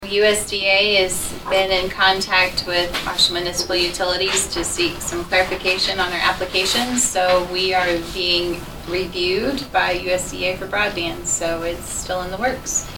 Northern District Commissioner Stephanie Gooden says the county’s attempt to get a grant to help fund rural broadband service is still in the works.